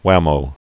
(wămō, hwămō)